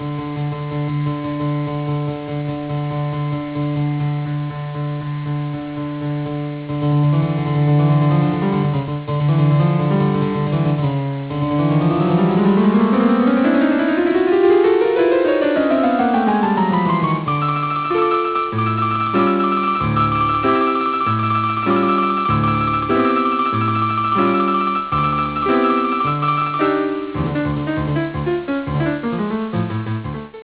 piano
baritone) coro e orchestra
Original track music: